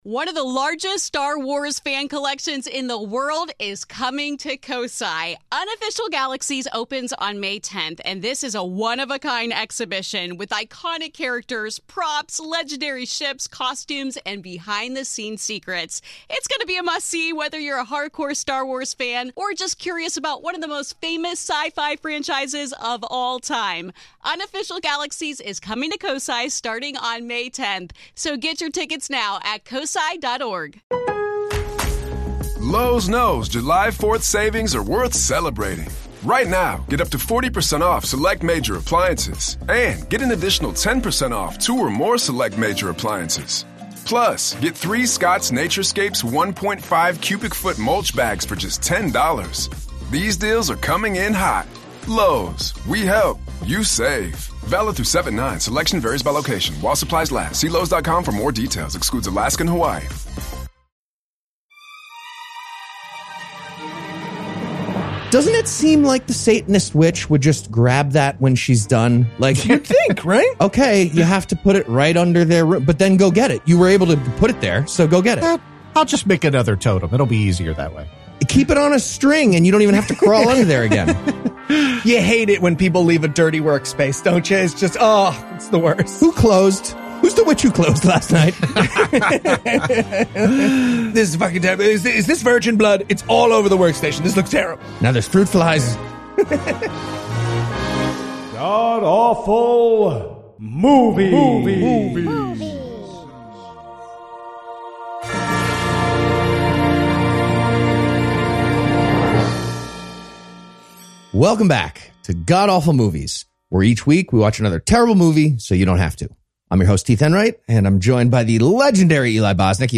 joins the guys for an atheist review